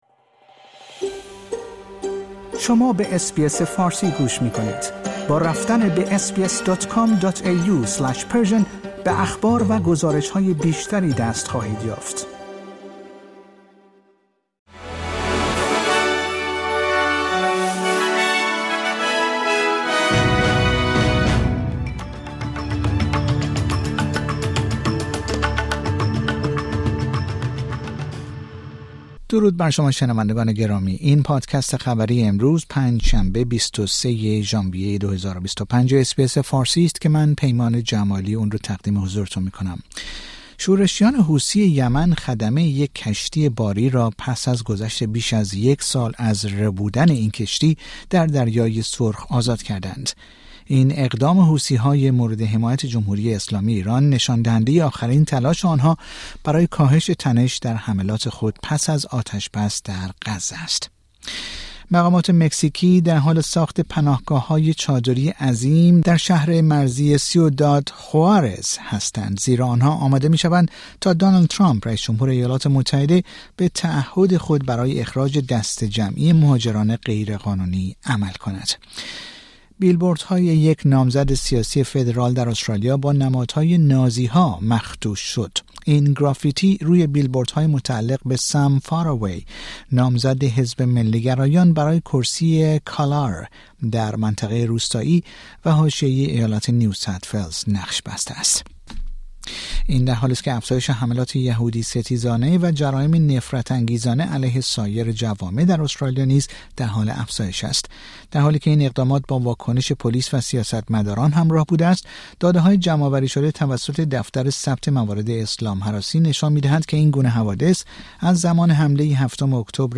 در این پادکست خبری مهمترین اخبار استرالیا در روز پنج شنبه ۲۳ ژانویه ۲۰۲۵ ارائه شده است.